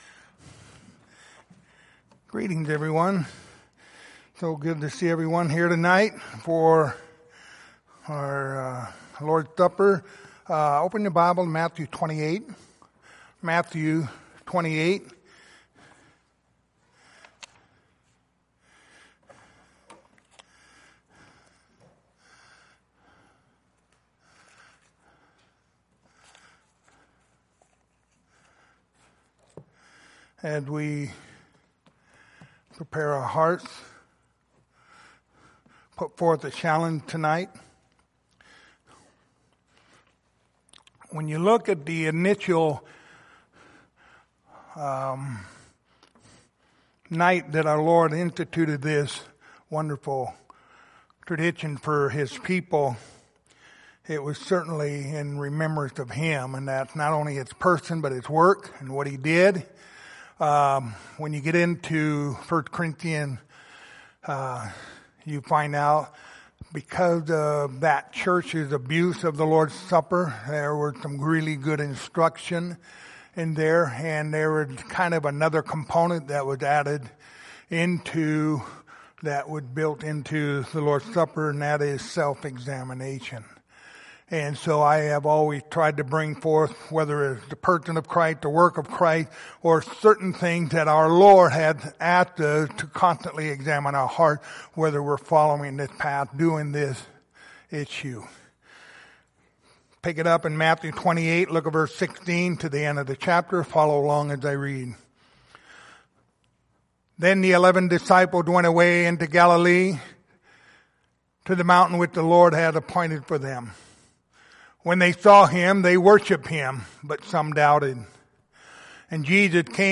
Lord's Supper Passage: Matthew 28:16-20 Service Type: Lord's Supper Topics